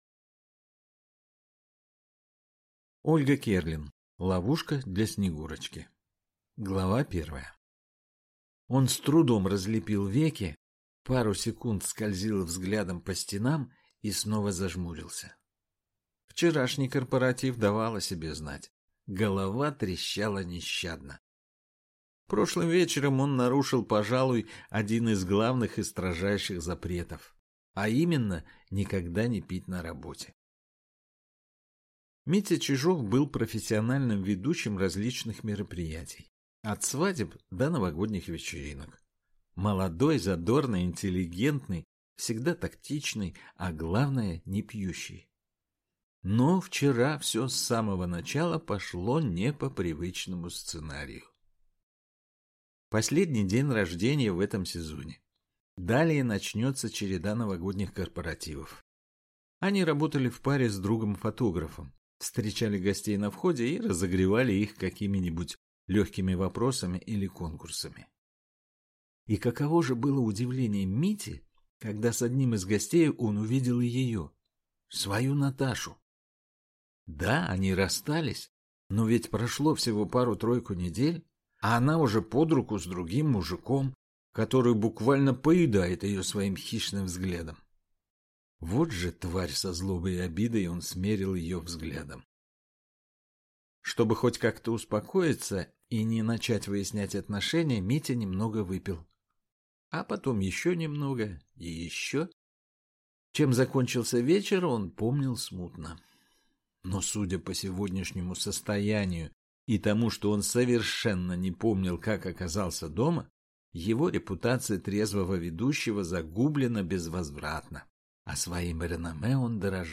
Аудиокнига Ловушка для Снегурочки | Библиотека аудиокниг